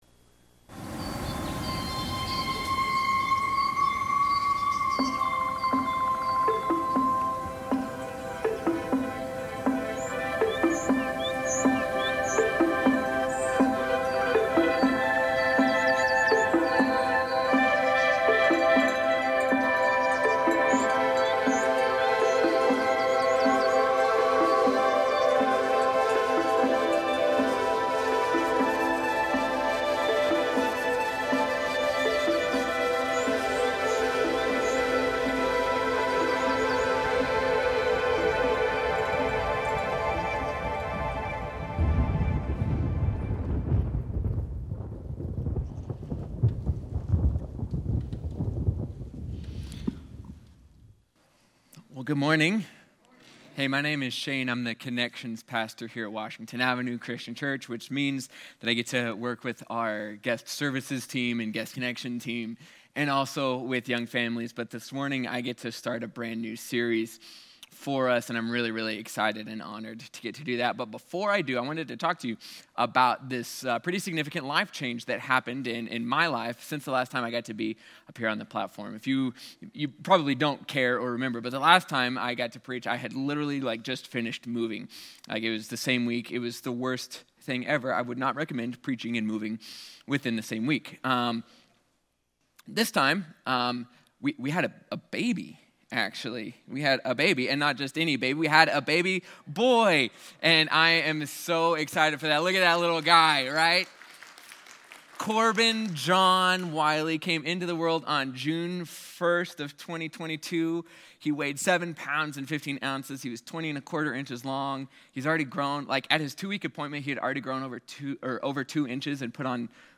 In this first sermon for the series Kingdom People